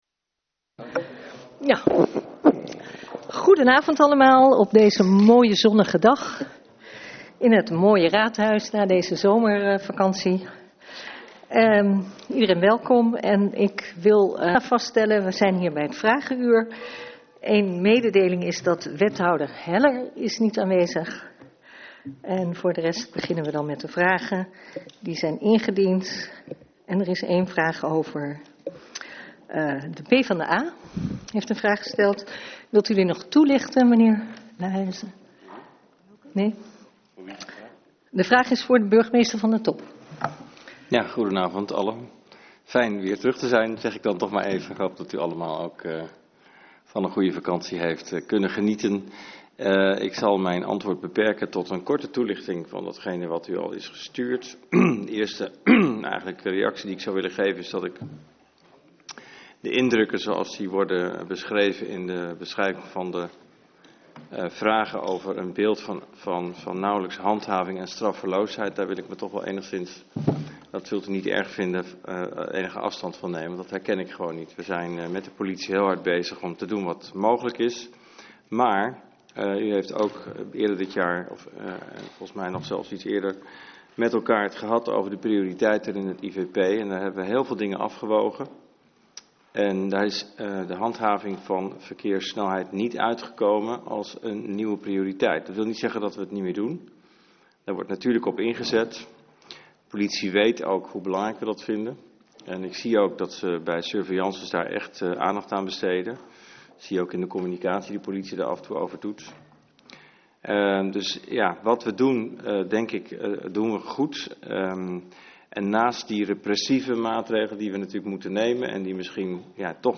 Locatie Raadzaal